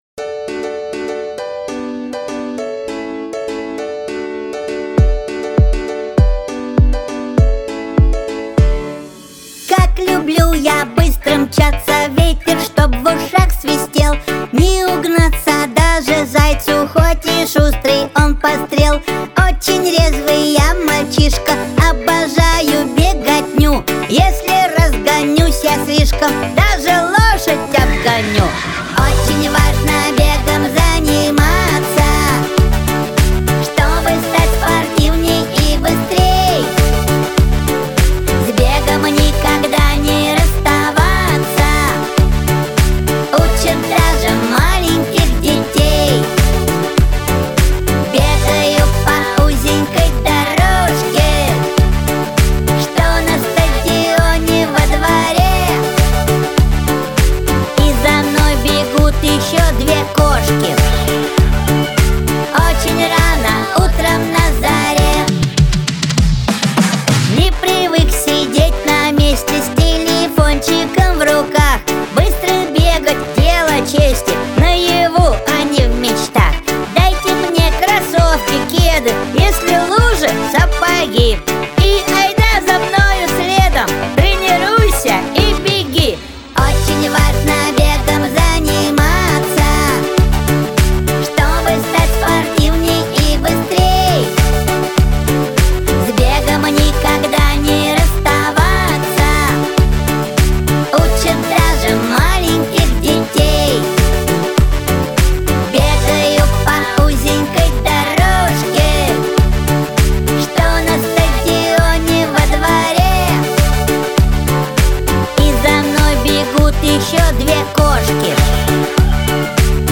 Аудиокнига Лёгкая атлетика | Библиотека аудиокниг